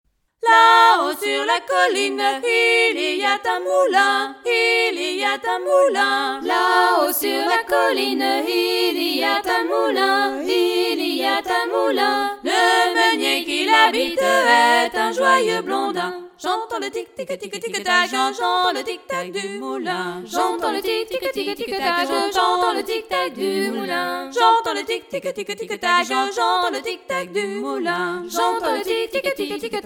danse : ronde : grand'danse
Pièce musicale éditée